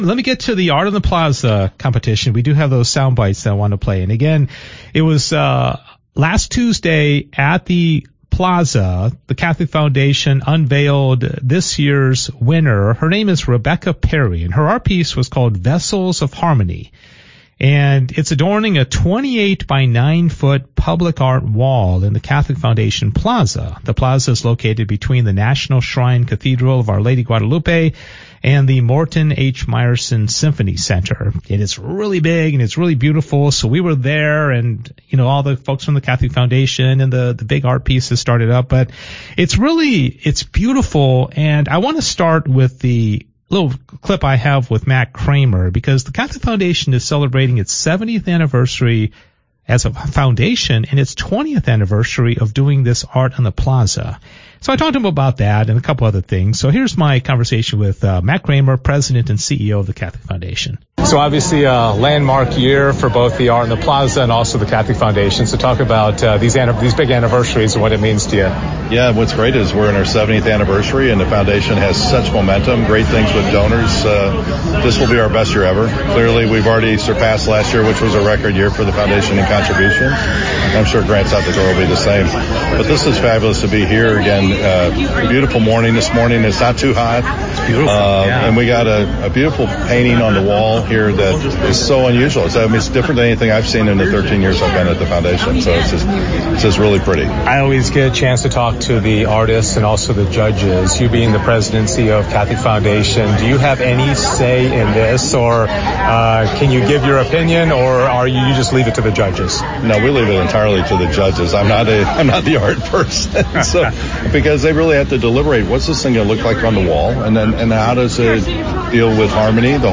2023 Submission Guidelines Read This Year’s News Release Watch Flyover Video of the Plaza in The Dallas Arts District Listen to Radio Interview About This Year’s Competition See The 2023 Art Wall Dedication Ceremony Photo Album